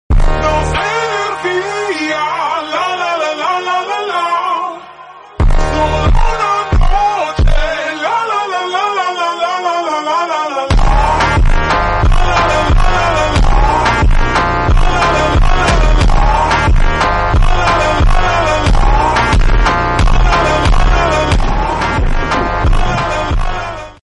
ultra slowed